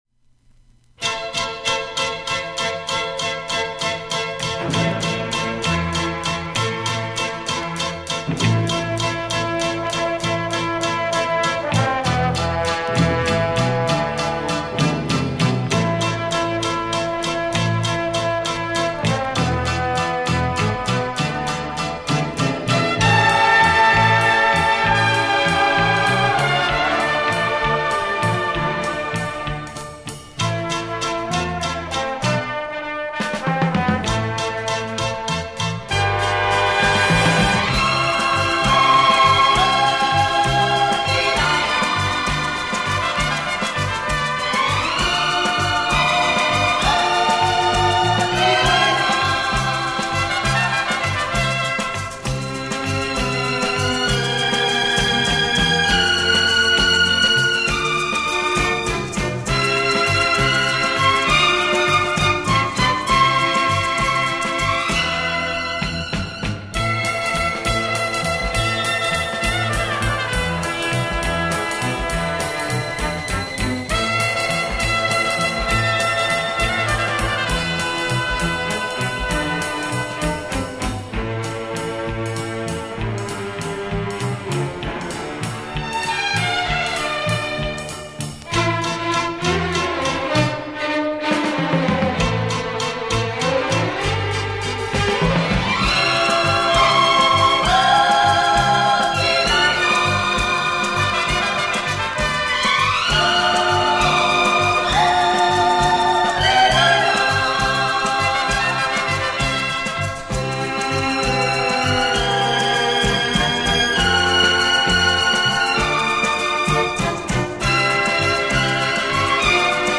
丝绸般的华丽弦乐，虽然是黑胶唱片，但音质不输CD，论音乐味道，还高于CD。